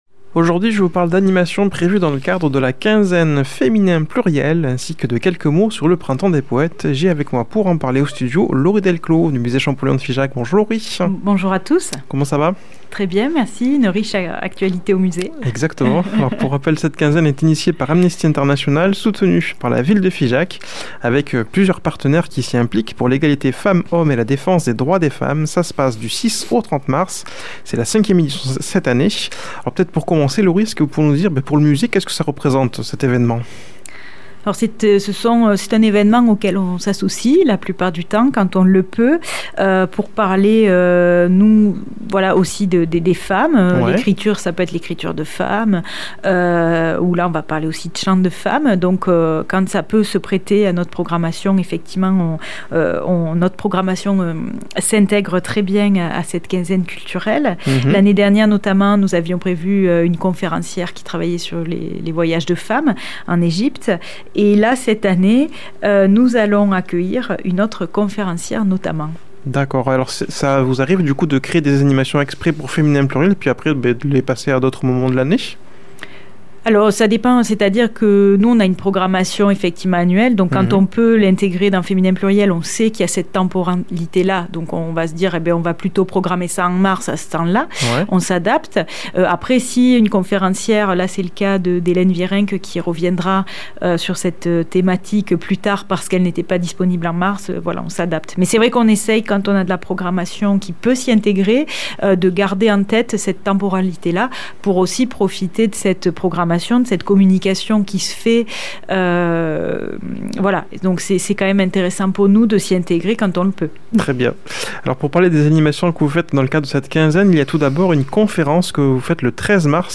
invitée au studio